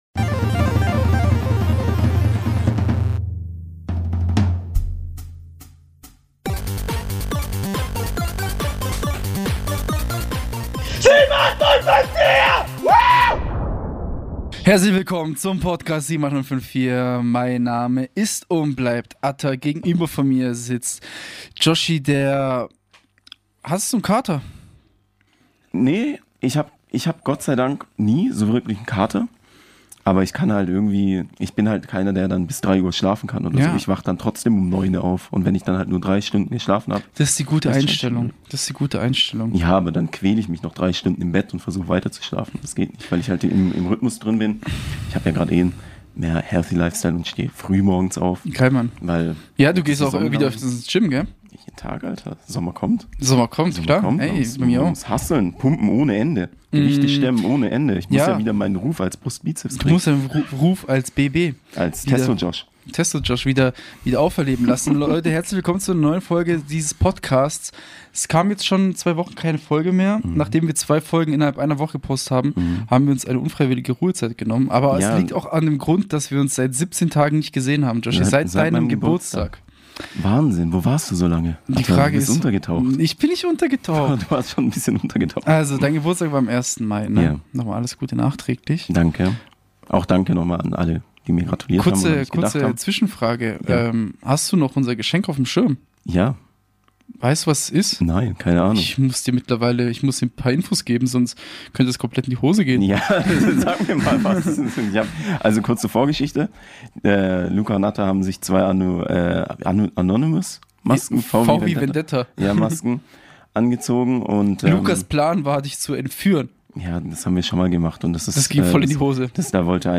International Festival an der HFU - Seid Live dabei.